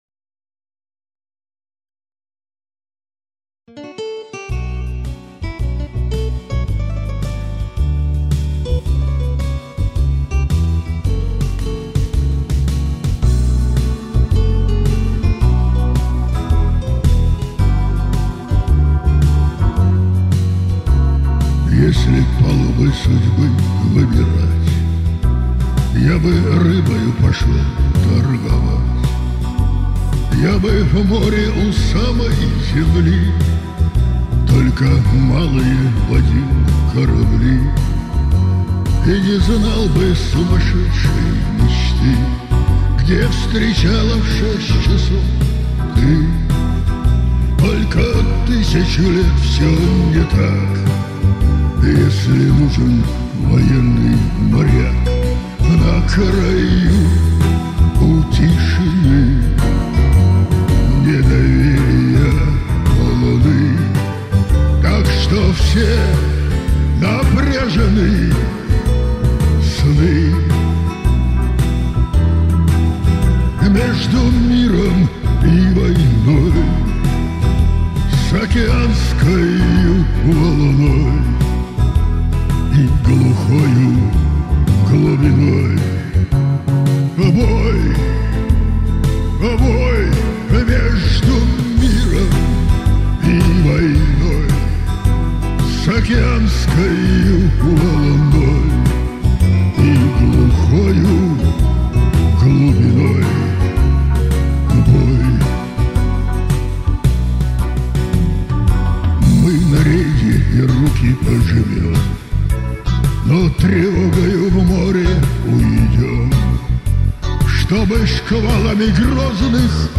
автор-исполнитель